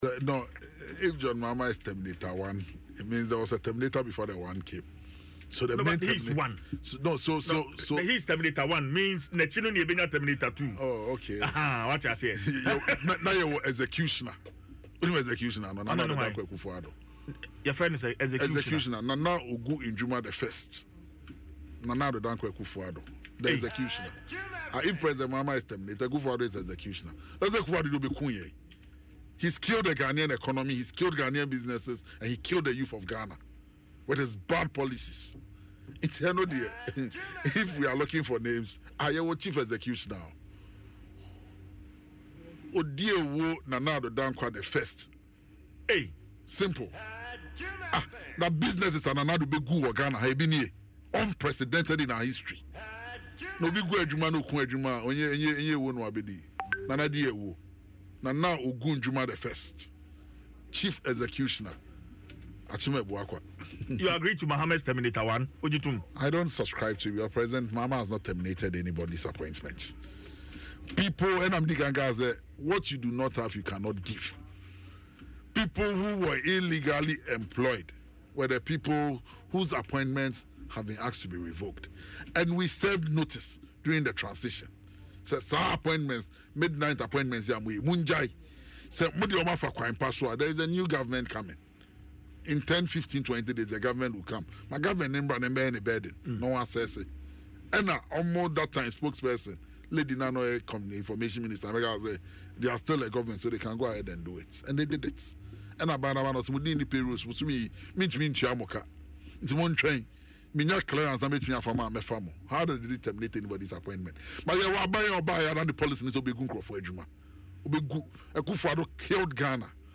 However, Opare Addo, who also serves as the National Youth Organiser of the National Democratic Congress (NDC), refuted the allegations in an interview on Adom FM’s Dwaso Nsem.